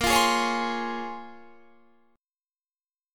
Bbm9 Chord
Listen to Bbm9 strummed